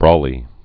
(brôlē)